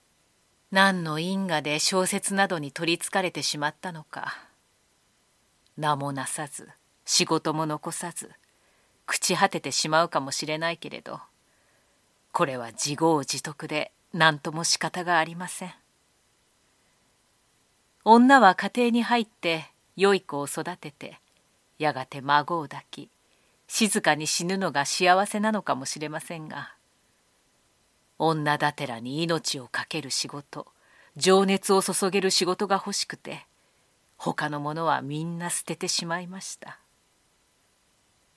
ボイスサンプル
朗読1